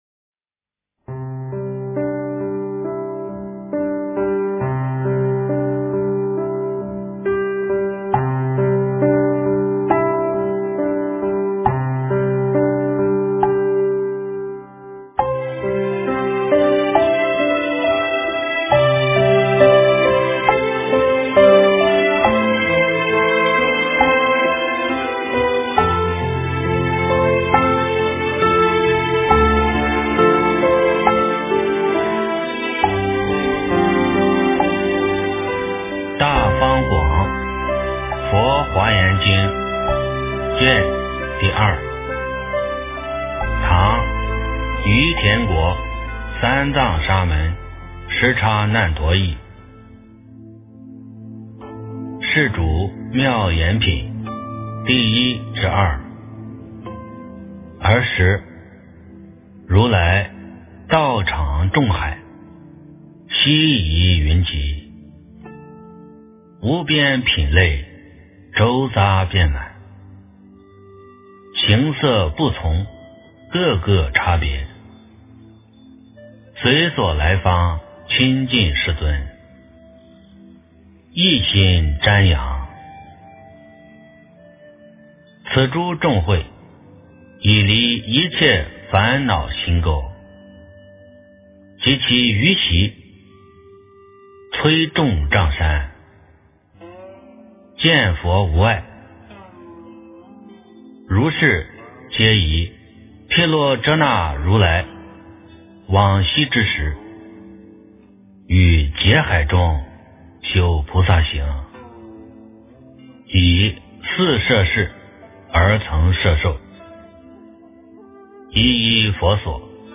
《华严经》02卷 - 诵经 - 云佛论坛